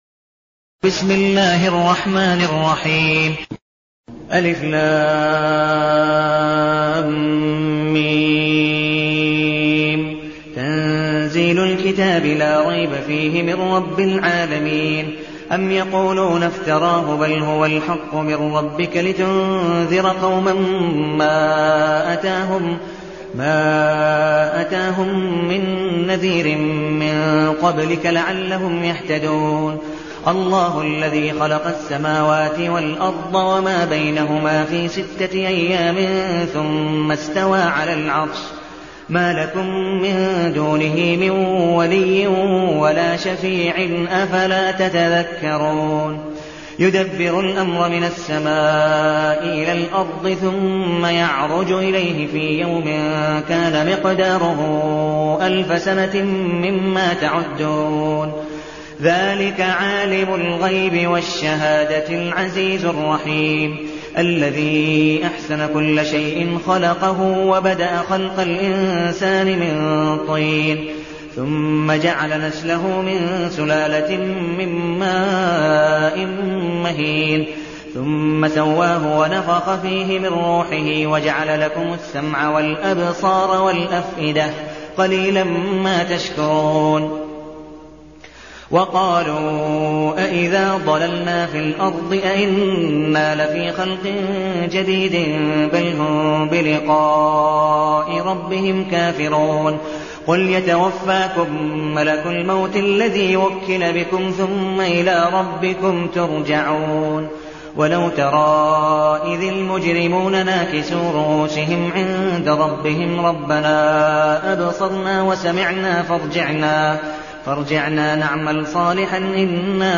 المكان: المسجد النبوي الشيخ: عبدالودود بن مقبول حنيف عبدالودود بن مقبول حنيف السجدة